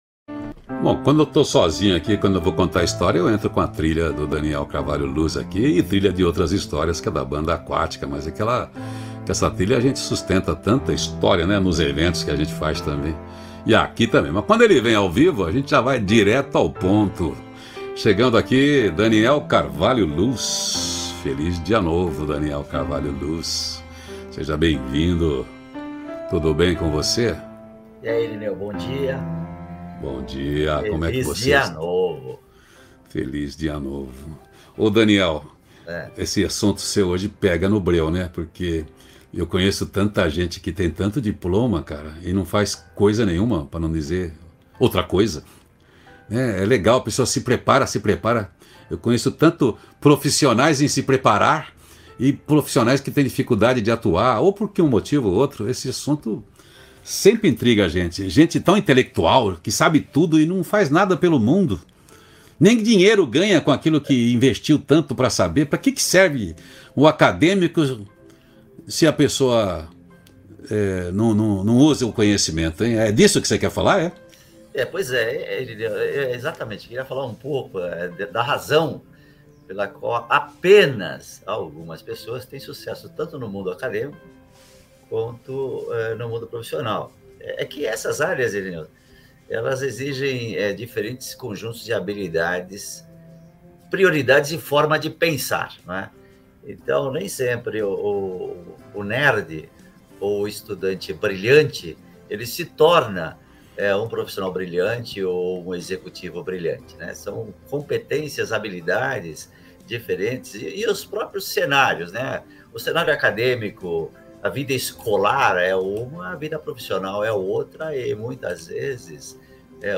Conversa com quem tem o que dizer.